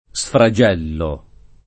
sfragello [ S fra J$ llo ]